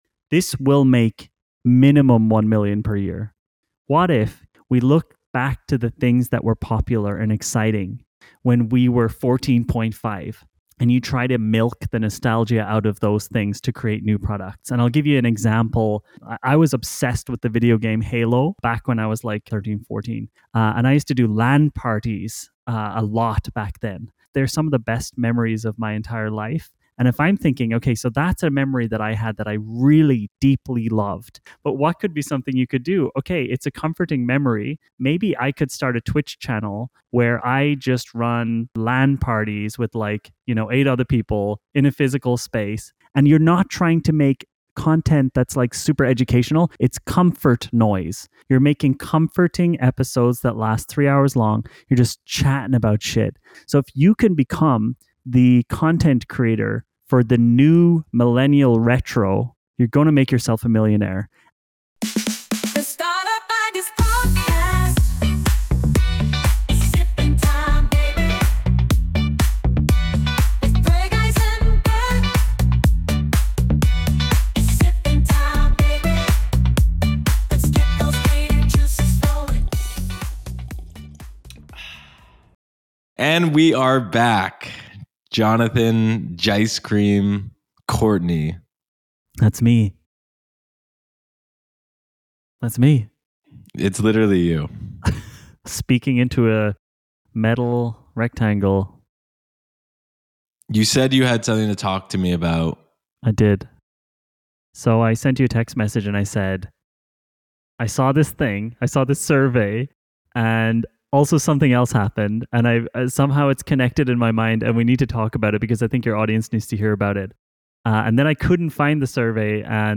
From retro gaming to high-end collectibles, he explores lucrative ideas that tap into sentimental connections. The conversation also dives into the rise of nostalgic gaming communities and the potential for startups to thrive by blending old memories with innovative content.